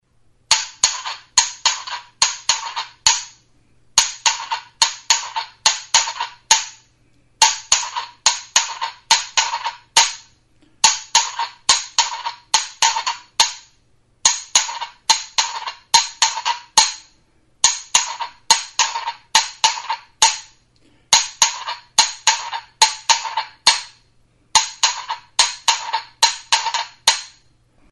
Recorded with this music instrument.
CONCHAS; RASCAS
Idiophones -> Struck -> Directly
Bi itsas-maskor konkortu eta hozkatu dira.